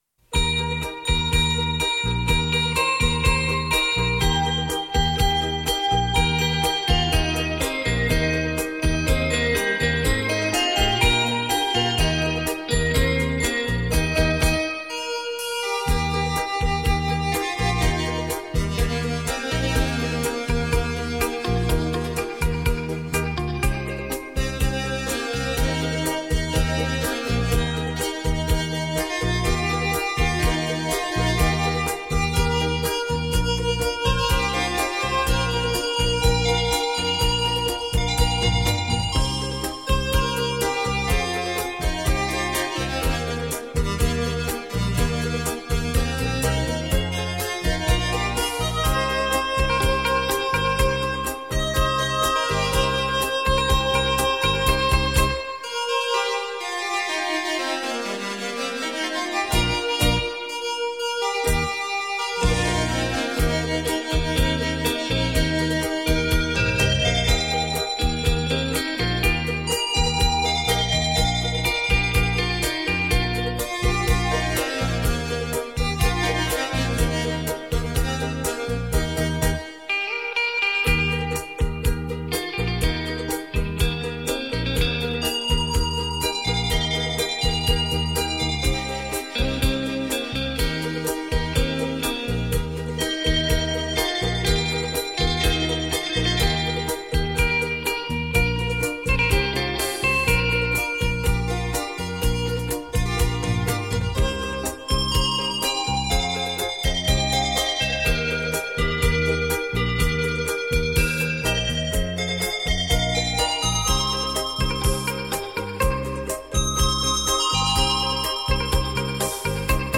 本CD母带经美国太平洋微音公司HDCD二型处理器处理